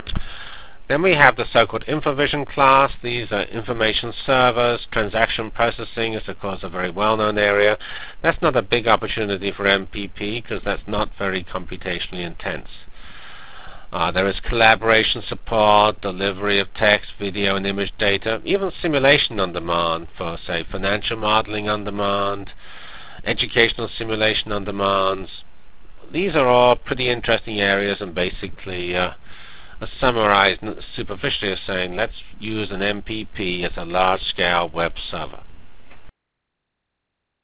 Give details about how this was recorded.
From HPCS95 Keynote Presentation: HPC at the Crossroads Academic Niche or Economic Development Cornucopia HPCS95 Symposium -- July 10-12 Montreal Canada.